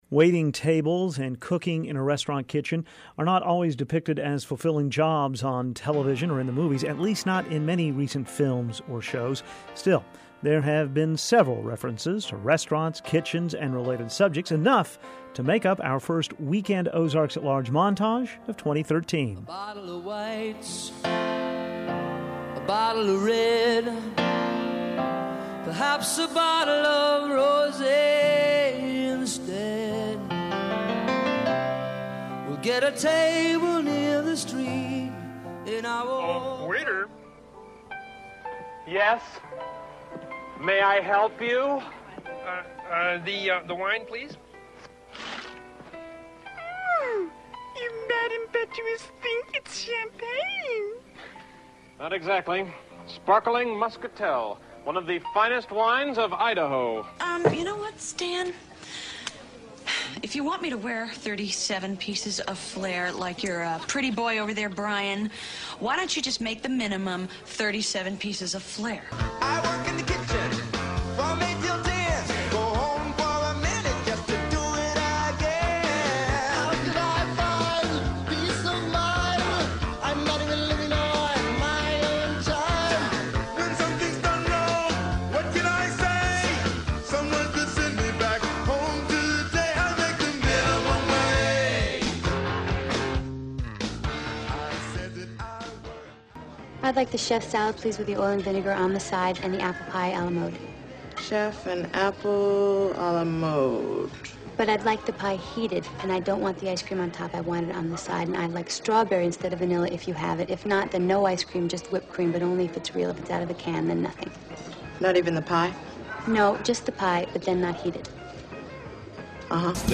Here is the list of pop culture references included in today's montage honoring food service workers: Billy Joel sings "Scenes from an Italian Restaurant."